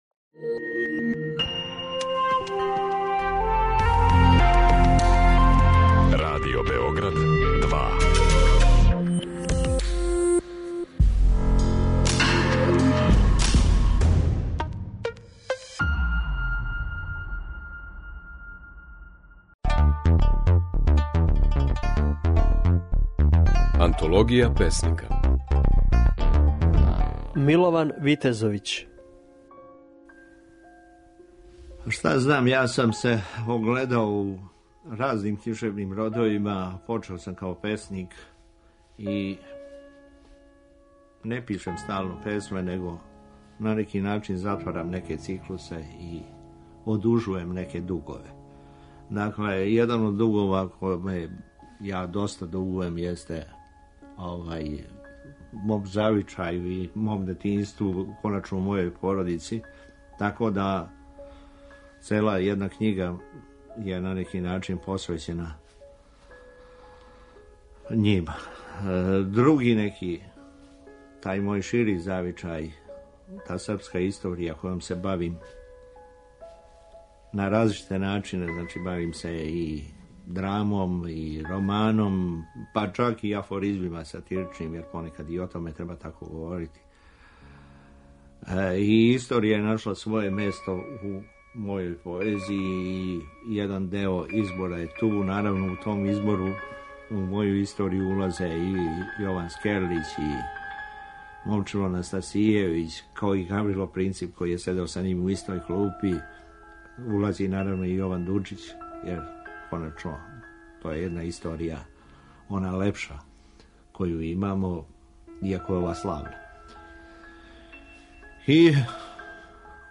Mожете слушати како своје стихове говори Милован Витезовић, (Витезовићи код Косјерића 11. септембра 1944. године).